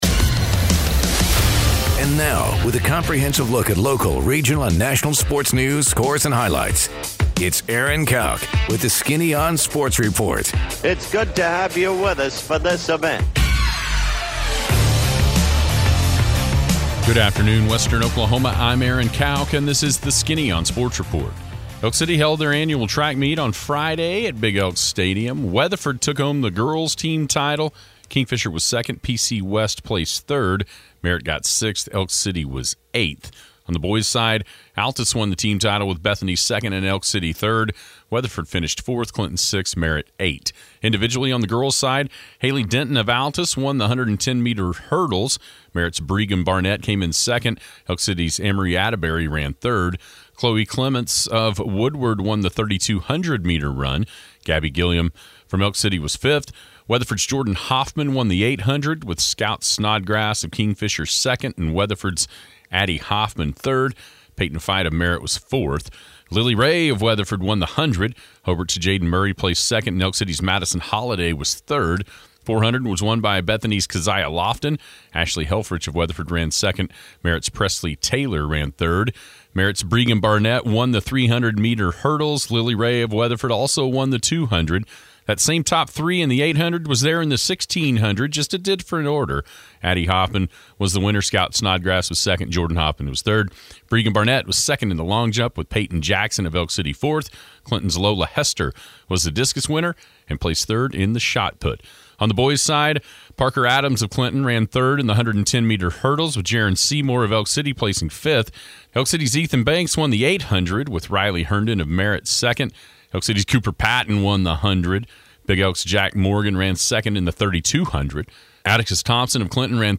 Sports Report